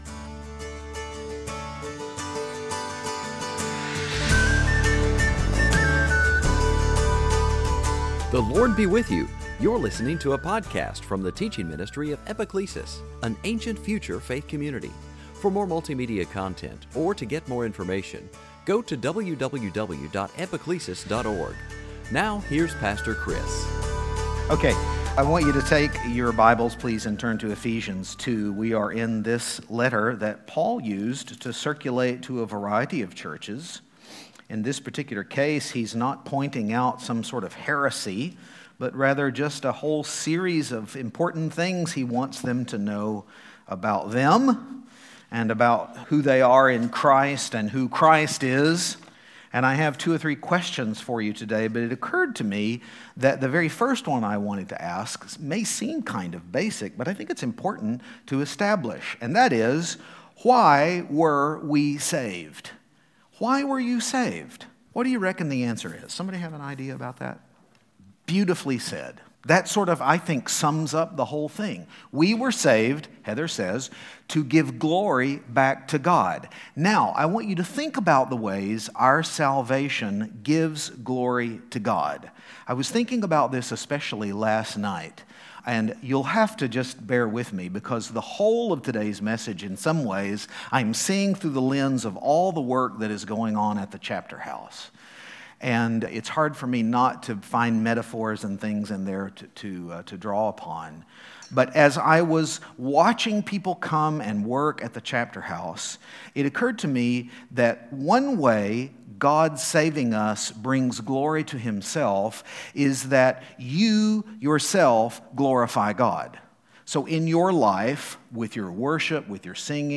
Series: Sunday Teaching
Service Type: Season after Pentecost